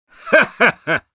(Короткий смешок 1)
Sniper_laughshort01_ru.wav